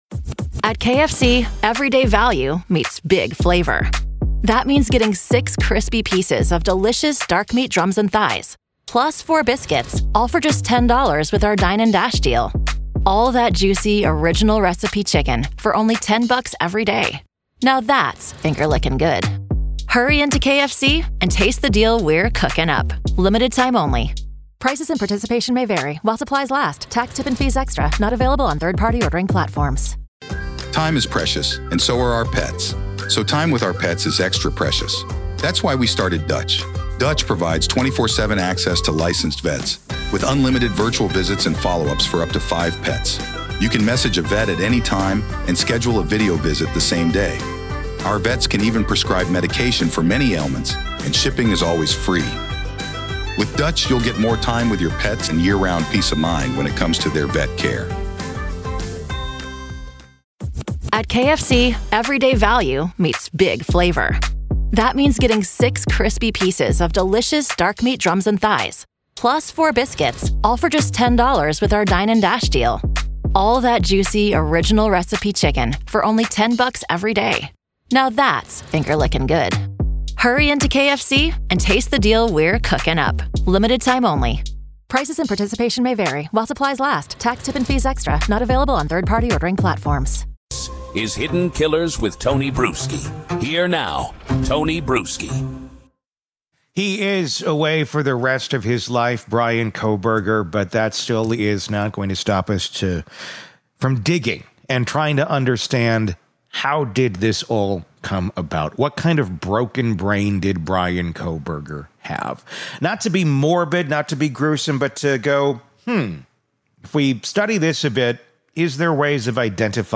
Full Interview: FBI Agent Breaks Down Bryan Kohberger’s Dark Psychology